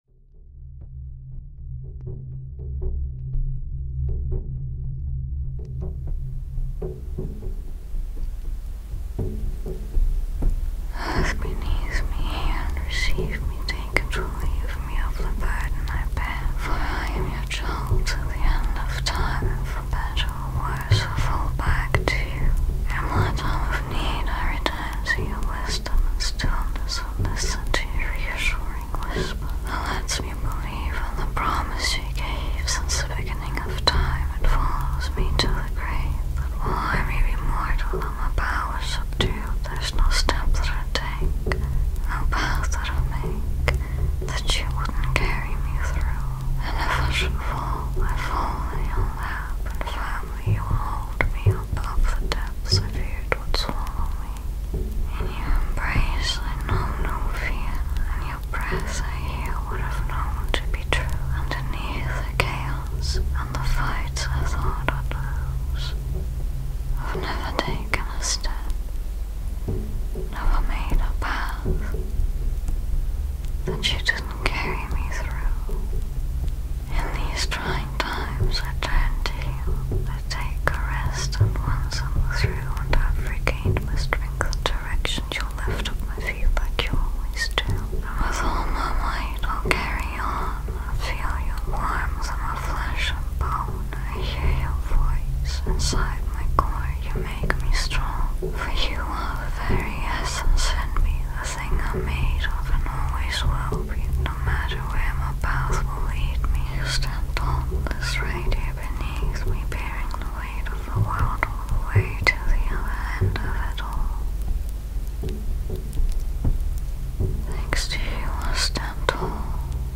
A Whispered Prayer To The Earth [ASMR].mp3